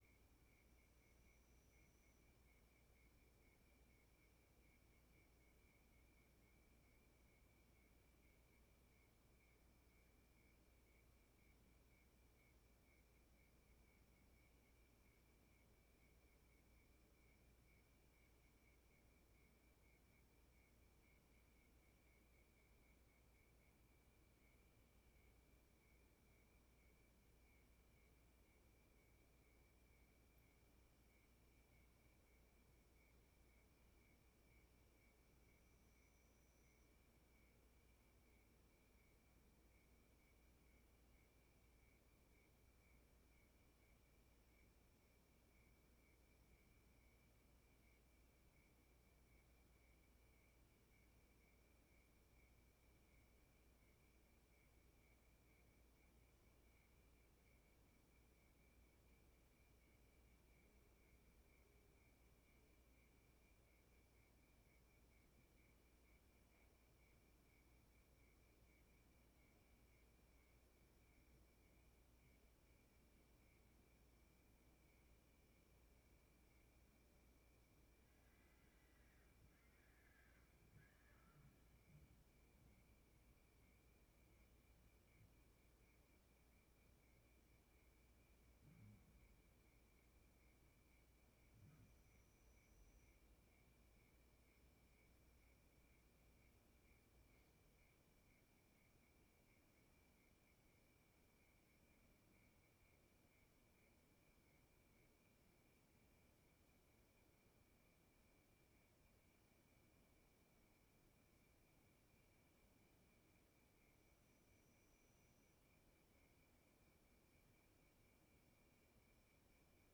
Ambiencia rural bem silenciosa de madrugada com grilo e as vezes morcego Animais , Grilo , Insetos , Madrugada , Morcego , Noite , Rural , Silencioso Cavalcante Stereo
CSC-03-012-LE - Ambiencia rural bem silenciosa de madrugada com grilo e as vezes morcego.wav